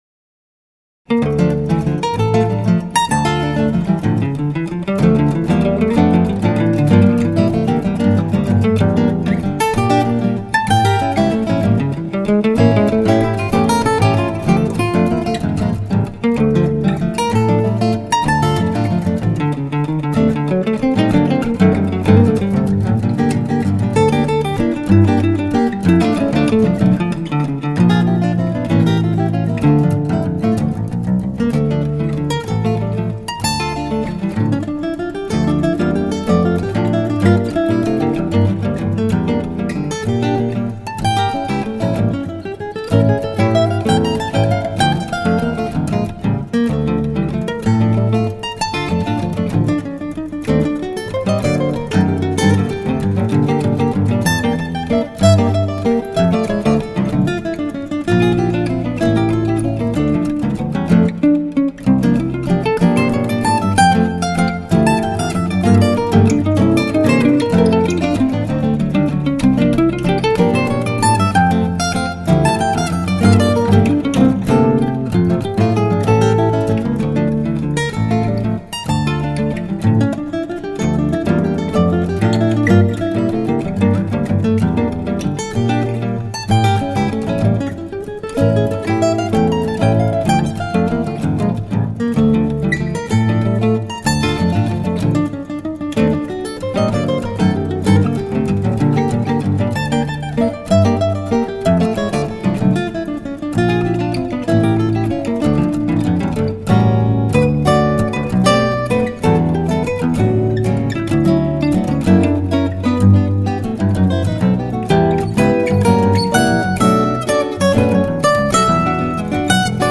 Quatuor de guitares
Accordéon